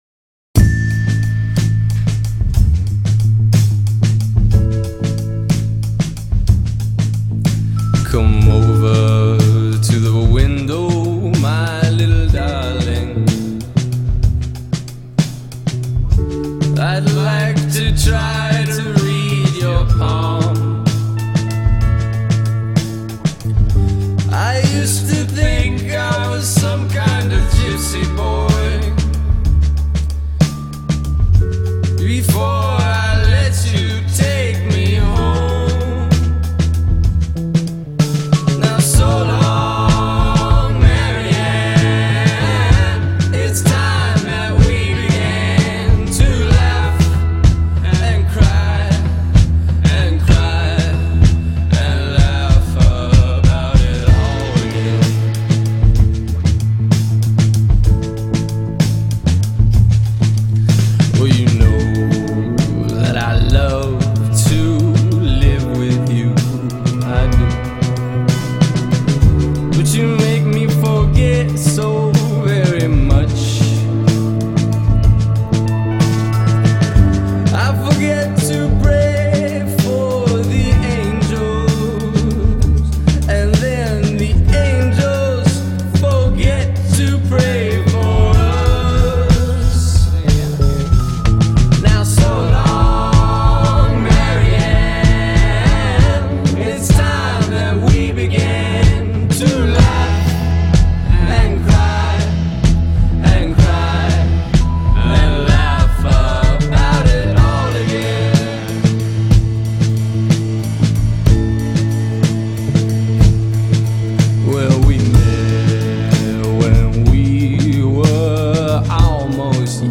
urgent and funky